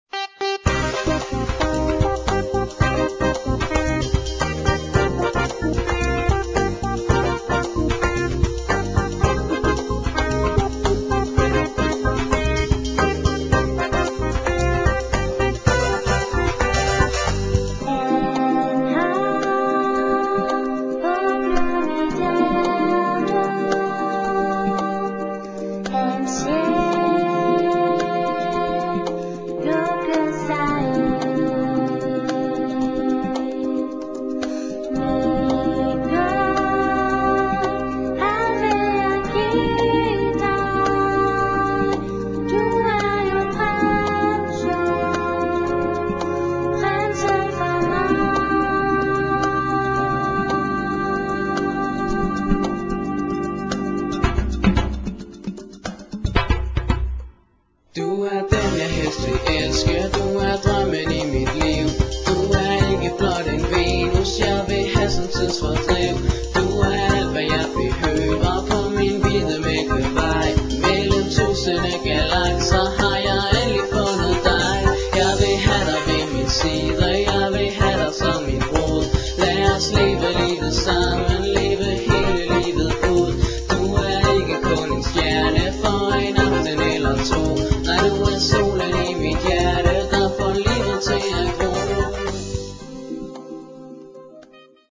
Lutter sangbare og ørehængende slagere finder man her!